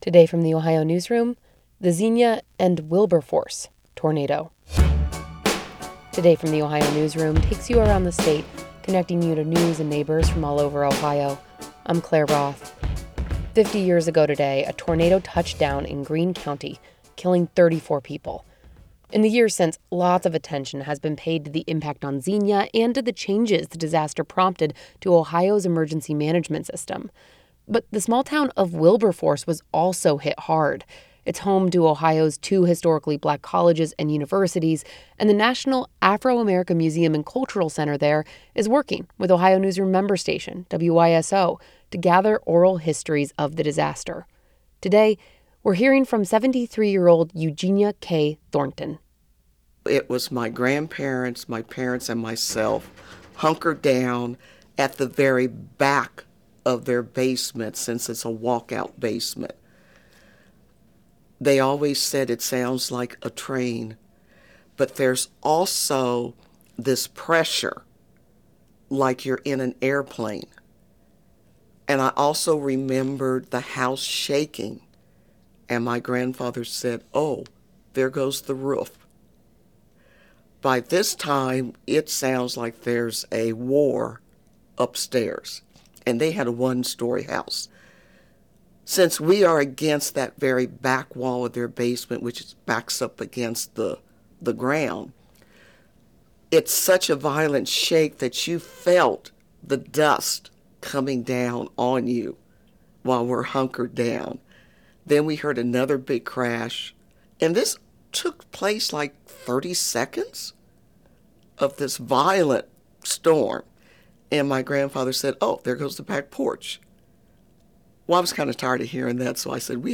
This interview has been edited for clarity.